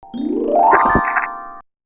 UI_SFX_Pack_61_40.wav